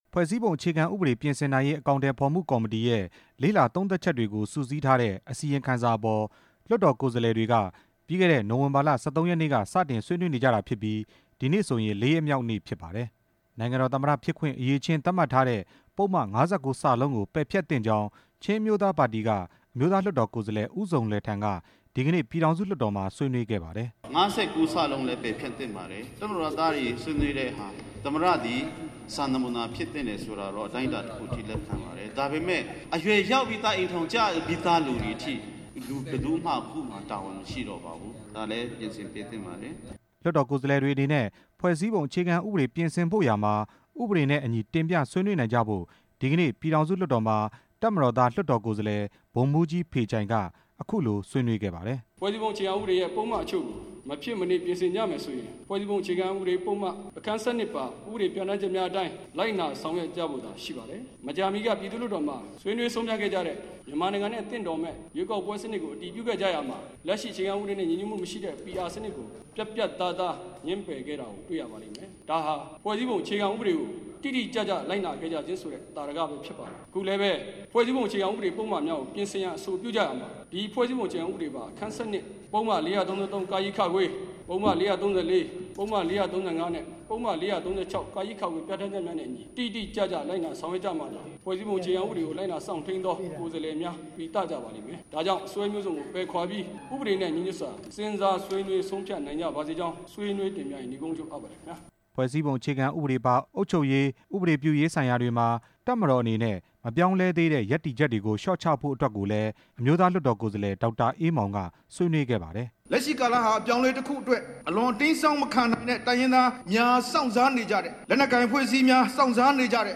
ဖွဲ့စည်းပုံအခြေခံဥပဒေ ပြင်ဆင်နိုင်ရေး အကောင်အထည်ဖော်မှုကော်မတီရဲ့ အစီရင်ခံစာအပေါ် ၄ ရက်မြောက်နေ့ အဖြစ် လွှတ်တော်ကိုယ်စားလှယ် ၂၄ ဦးက ဆွေးနွေးရာ အမျိုးသားလွှတ်တော်ကိုယ်စားလှယ် ဦးဇုန်လှယ်ထန်းက အခုလိုဆွေးနွေးခဲ့တာဖြစ်ပါတယ်။